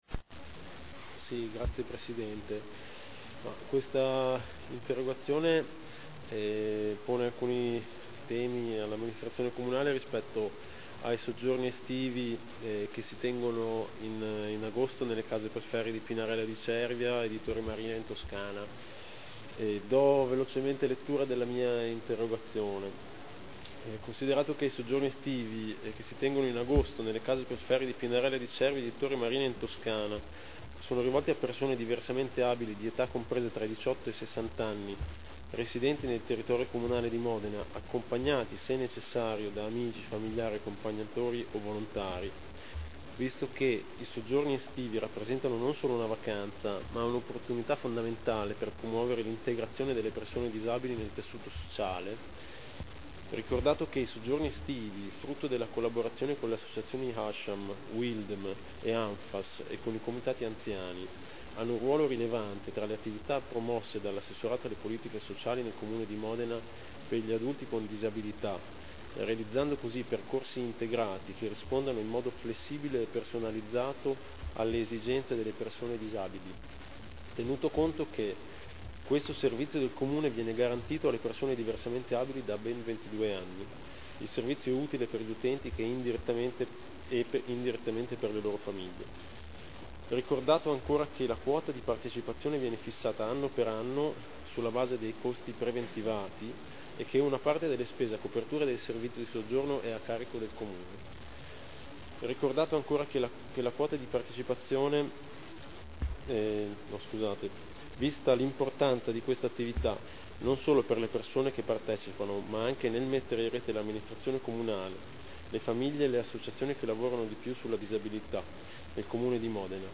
Stefano Rimini — Sito Audio Consiglio Comunale